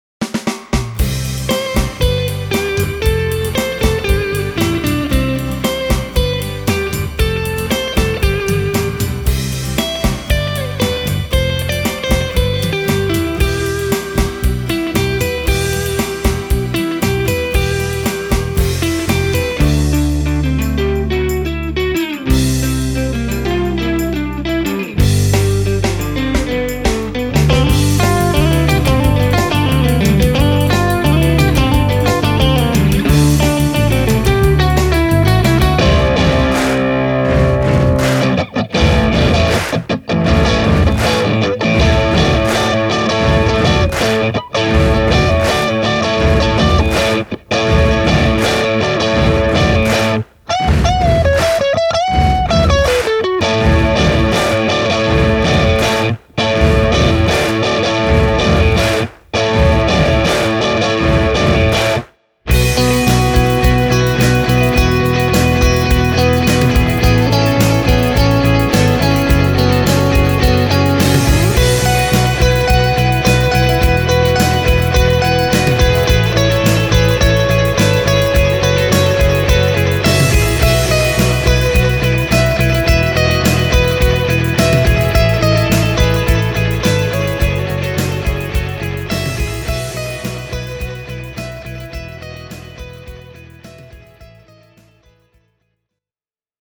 Mikrofonit: AKG C3000 & Shure SM57
Delay-efektejä on lisätty miksausvaiheessa: